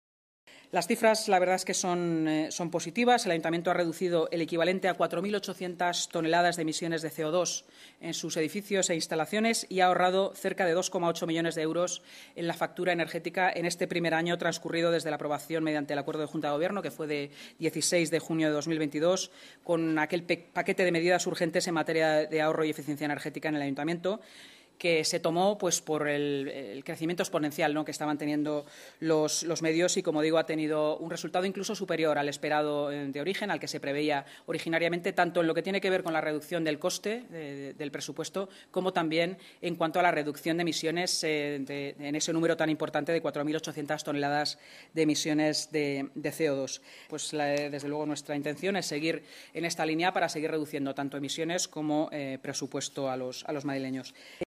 Nueva ventana:Así lo ha informado la vicealcaldesa de Madrid y alcaldesa en funciones, Inma Sanz, tras la Junta de Gobierno: